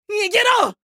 青年ボイス～ホラー系ボイス～